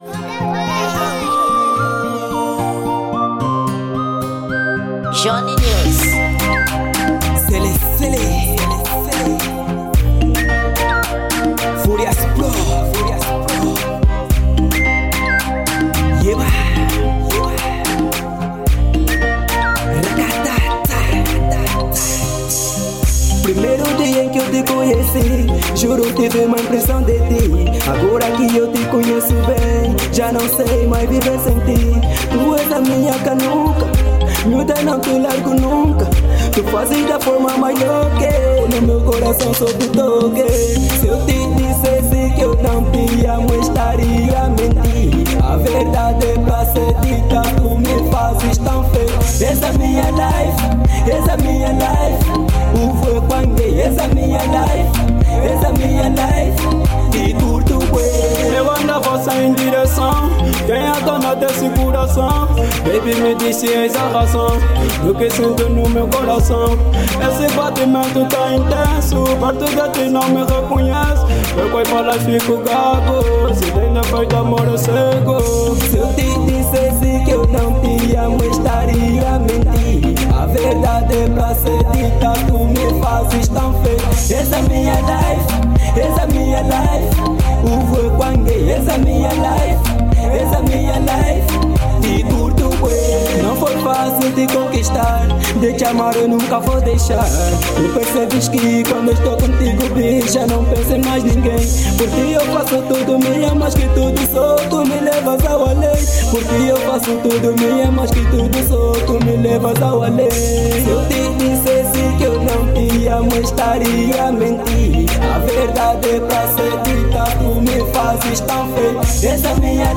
Gênero: Afro Naija